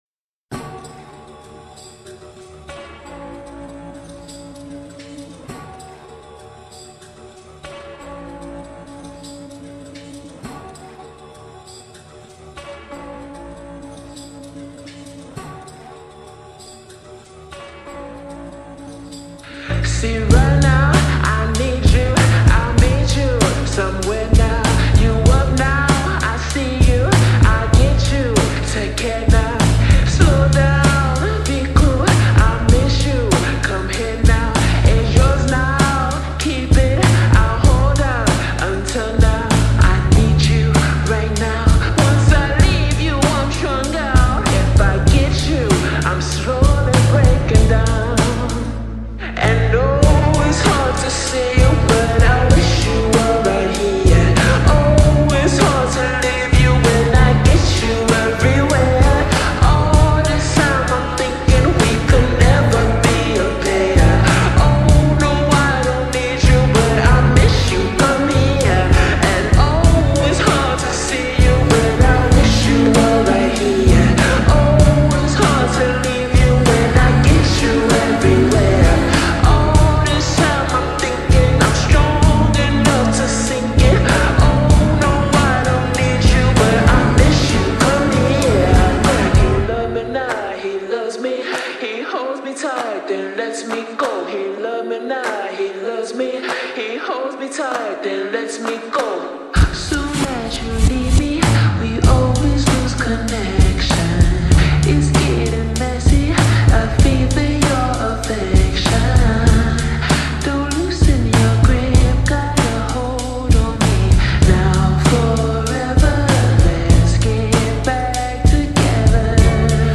نسخه Slowed
با ریتمی کند شده